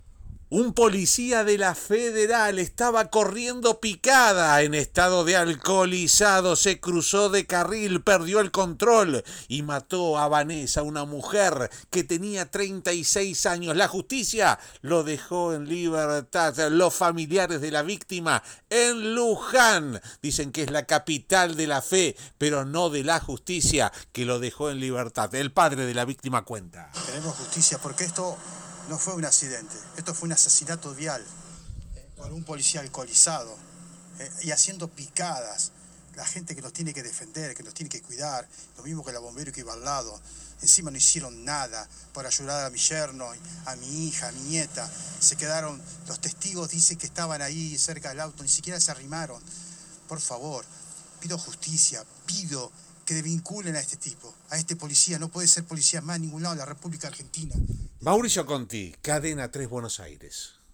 La vicepresidente del país vecino dijo a Cadena 3 que el gobierno de Luis Lacalle Pou optó por el quedarnos en casa pero no en forma obligatoria. El resultado es menos de mil contagios.